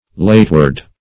Search Result for " lateward" : The Collaborative International Dictionary of English v.0.48: Lateward \Late"ward\, a. & adv. Somewhat late; backward.